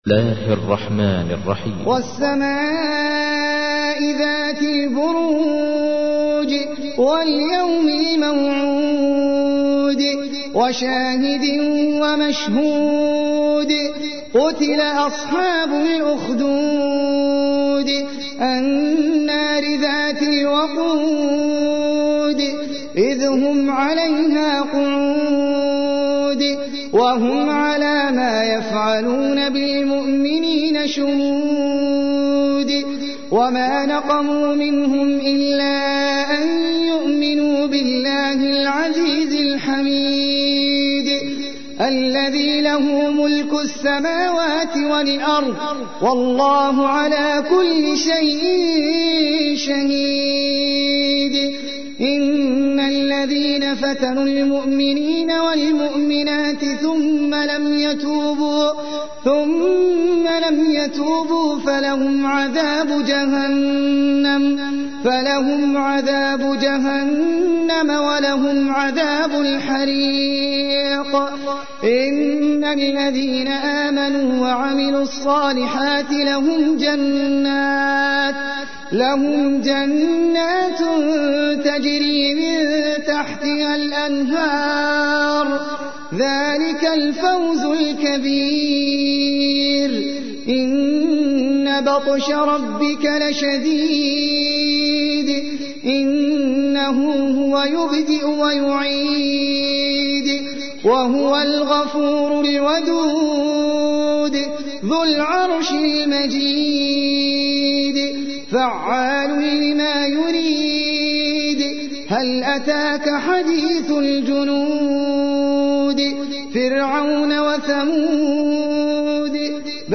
تحميل : 85. سورة البروج / القارئ احمد العجمي / القرآن الكريم / موقع يا حسين